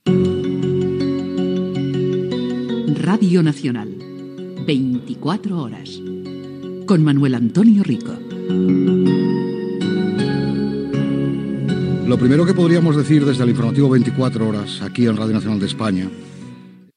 Careta del programa i salutació inicial
Informatiu